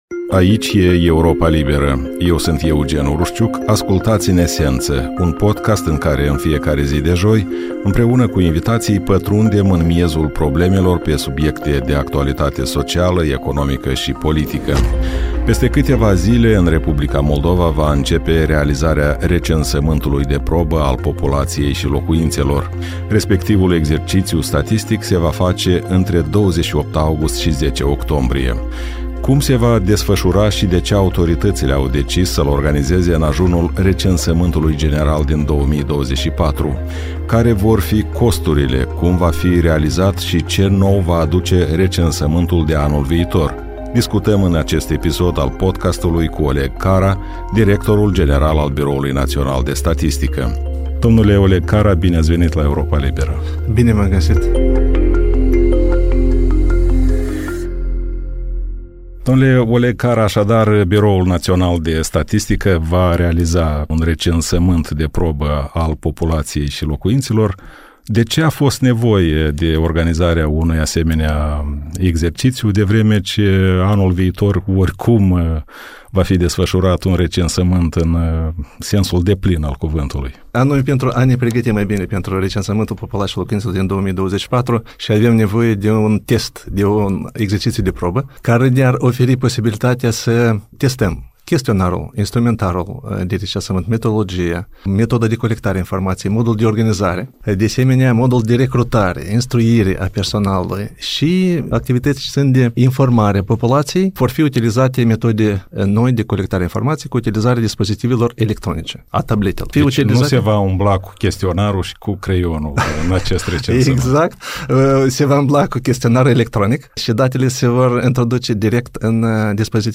Recensământul populației și locuințelor din 2024 se va desfășura cel mai probabil în primăvară și ar putea dura două luni, a spus Oleg Cara, directorul Biroului Național de Statistică în podcastul Europei Libere „În esență...”. Acesta ar putea fi și ultimul recensământ desfășurat în formulă clasică.